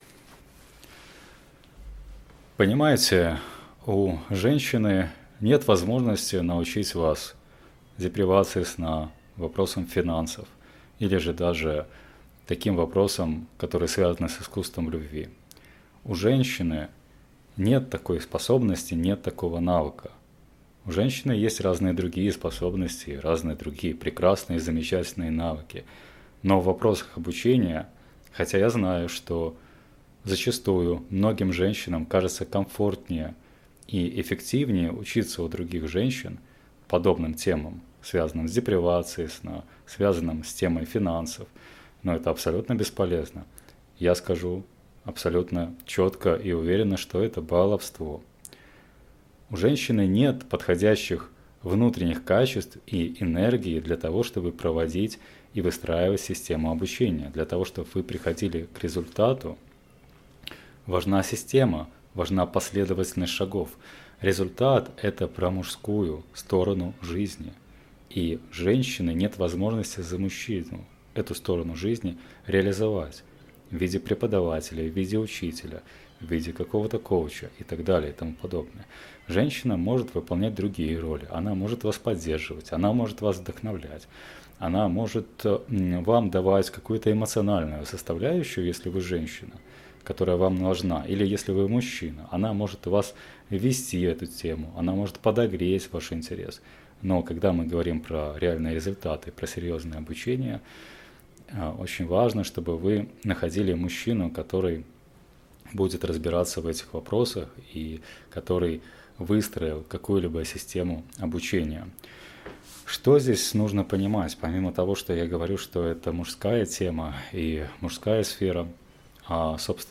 Голосовая заметка - Тег audio не поддерживается вашим браузером.